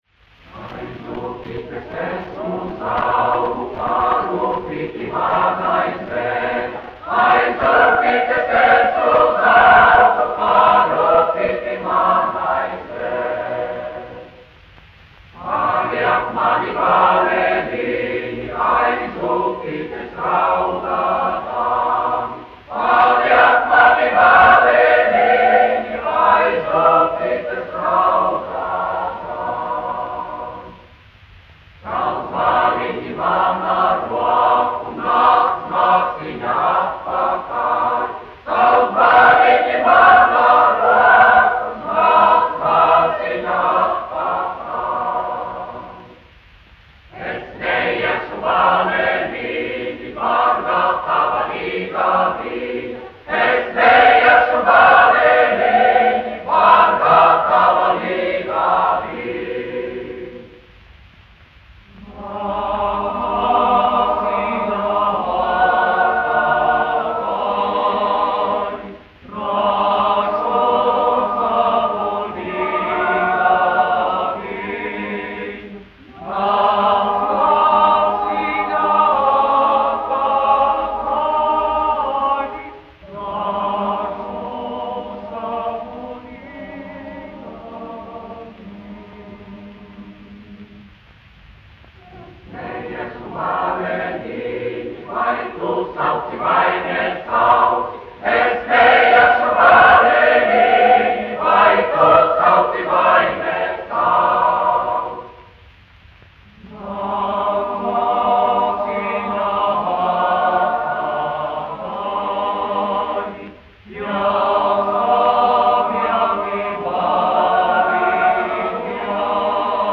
Reitera koris, izpildītājs
1 skpl. : analogs, 78 apgr/min, mono ; 25 cm
Latvijas vēsturiskie šellaka skaņuplašu ieraksti (Kolekcija)